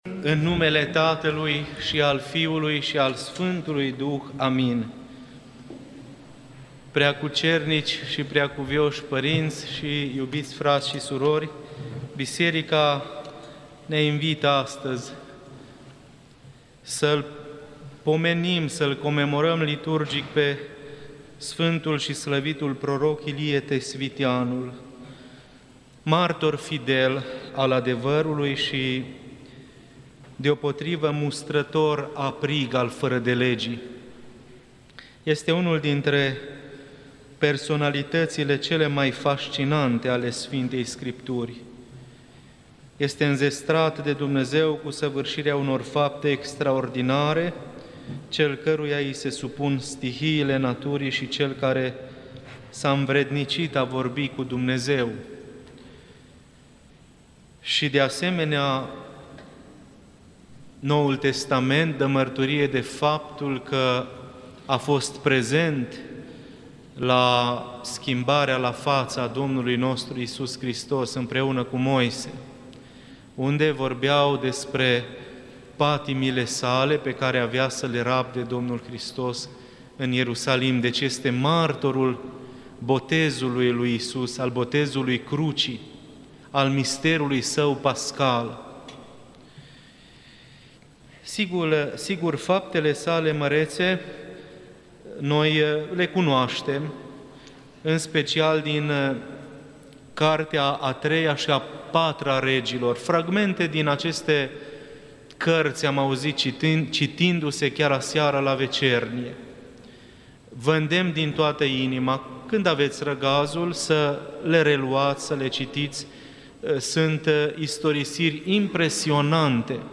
Predică la Sărbătoarea Sf. Prooroc Ilie Tesviteanul